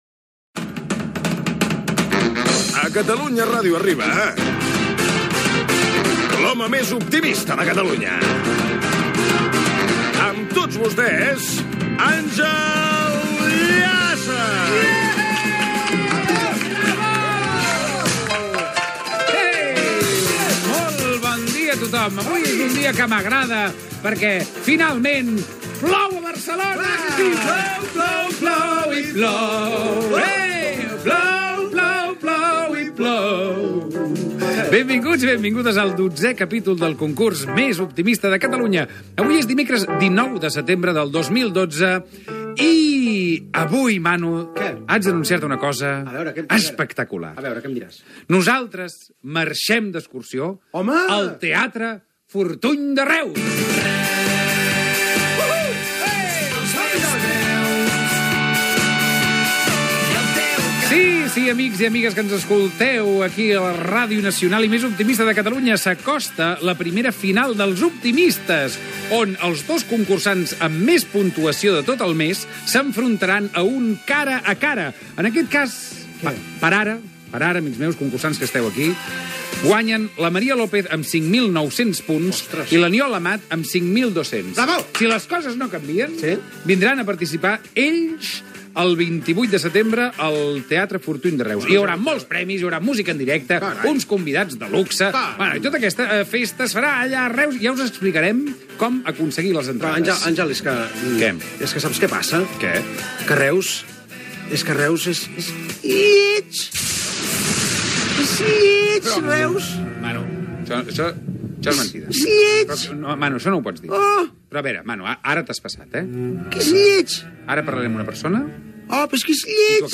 3b079e5bf4200ec9aaf871449ddf062cf01c2ead.mp3 Títol Catalunya Ràdio Emissora Catalunya Ràdio Cadena Catalunya Ràdio Titularitat Pública nacional Nom programa Els optimistes Descripció Careta del programa, presentació, comentari sobre la pluja i el programa que es farà a Reus, entrevista a Carles Pellicer, alcalde de Reus. Públic que assisteix a l'estudi.